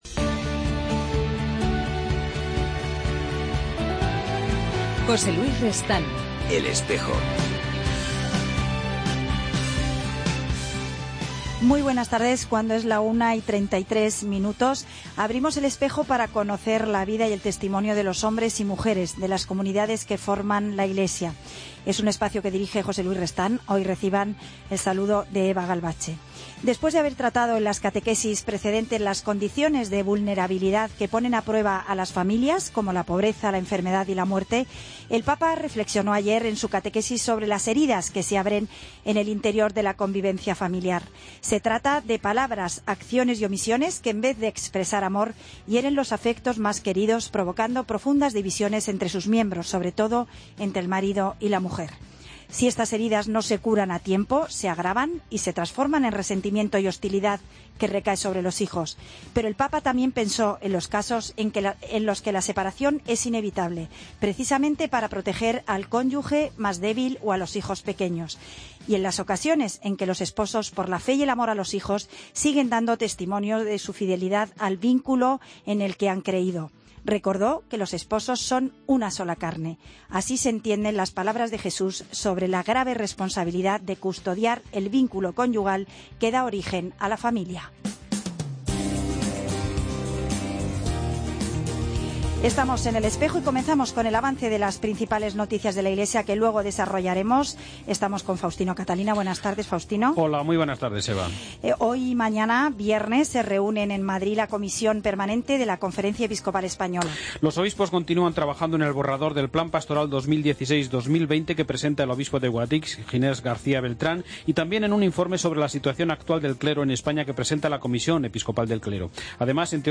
Hoy en El Espejo nos acercamos a una experiencia juvenil de misión, aprovechando el tiempo de verano. Nos acompañarán dos jóvenes madrileños